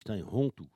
Localisation Saint-Jean-de-Monts
Catégorie Locution